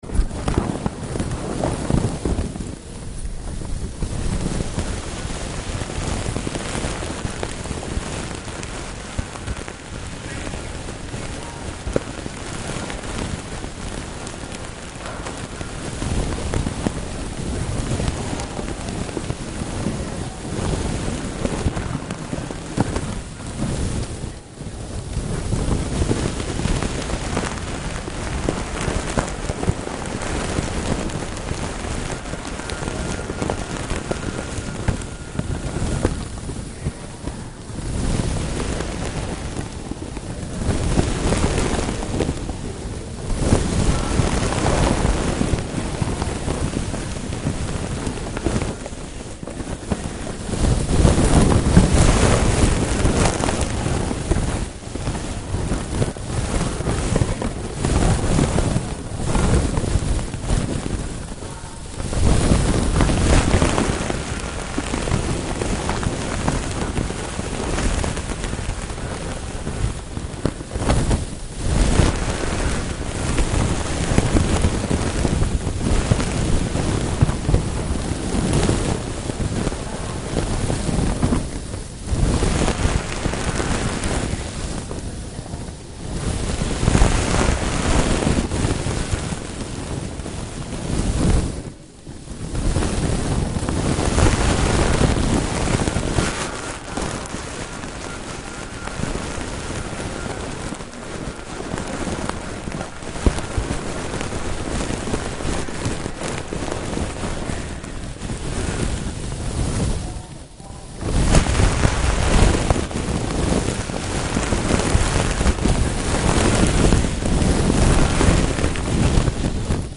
sound of moving my hands in the pile of magnetic tape